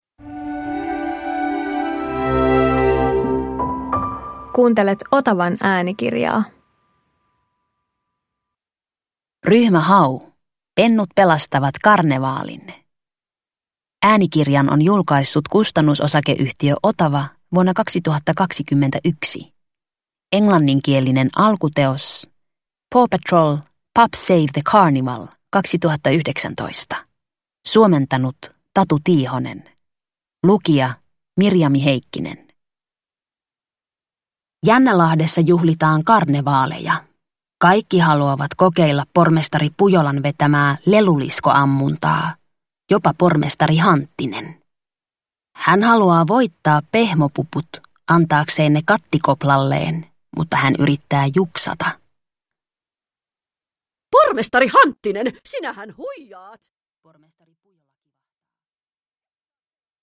Ryhmä Hau - Pennut pelastavat karnevaalin – Ljudbok – Laddas ner